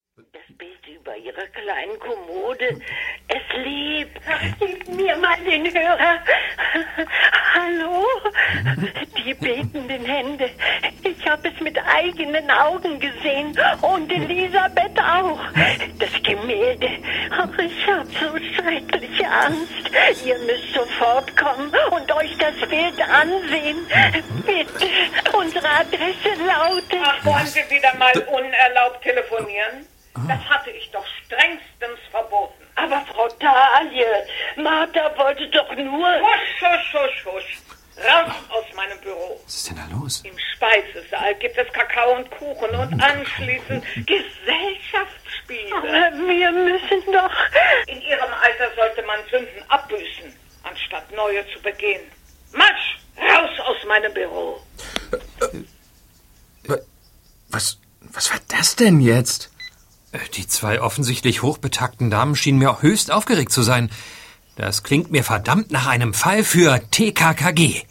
Ravensburger TKKG 171 - Das lebende Gemälde ✔ tiptoi® Hörbuch ab 6 Jahren ✔ Jetzt online herunterladen!